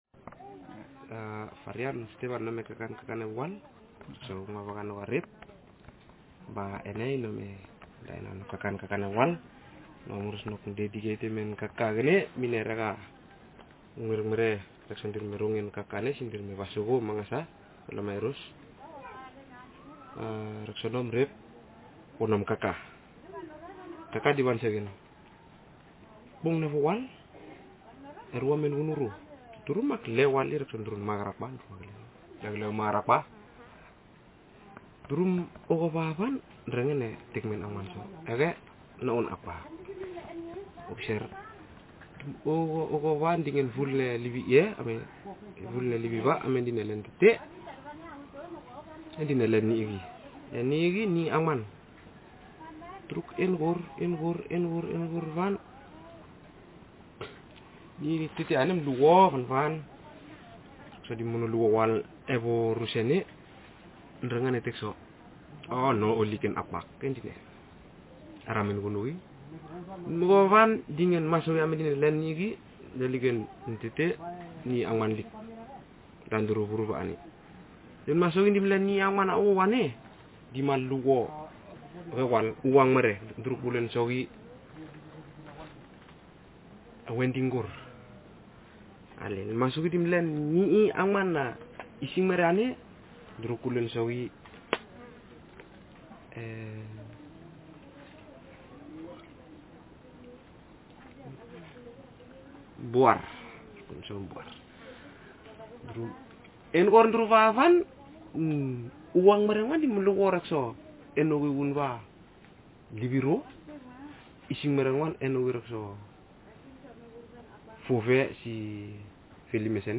Speaker sex m Text genre traditional narrative